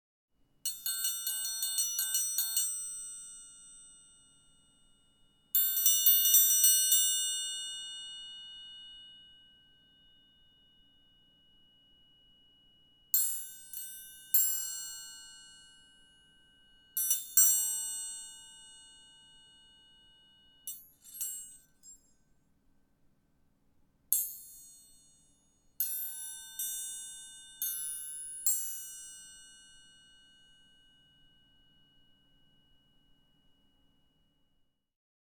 Triangle instrument
Ding High-pitched Instrument Metal Musical OWI Triangle sound effect free sound royalty free Music